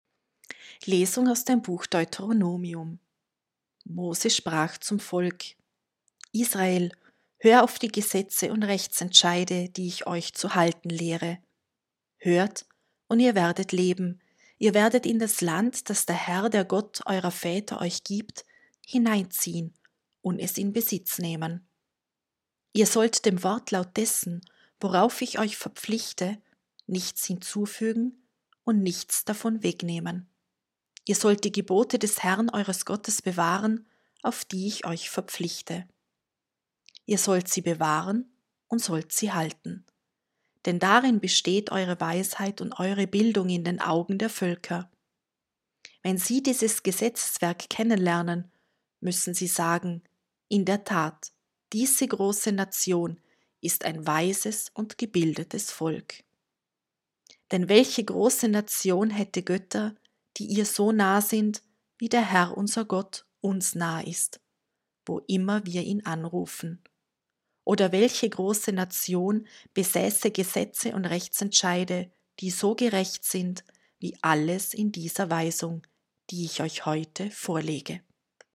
1.-Lesung-29.8..mp3